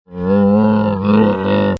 Camel 11 Sound Effect Free Download